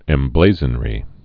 (ĕm-blāzən-rē)